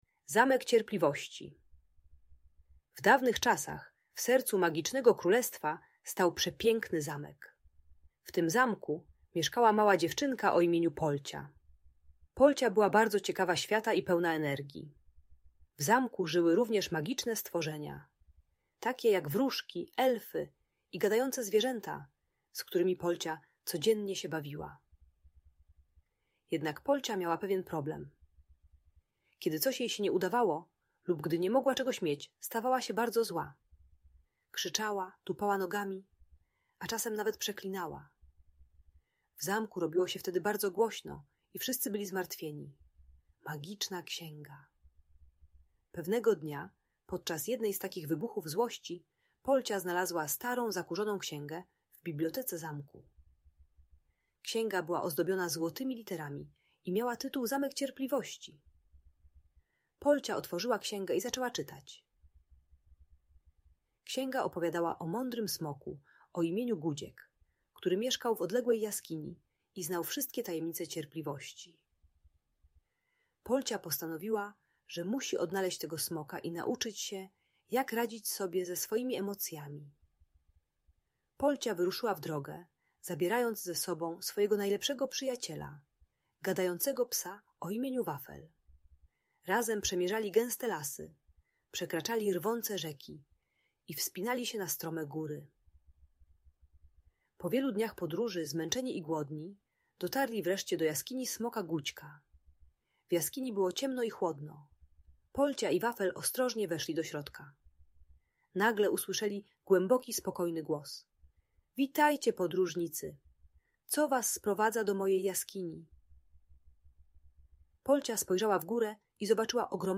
Zamek Cierpliwości: Magiczna Opowieść o Cierpliwości - Audiobajka dla dzieci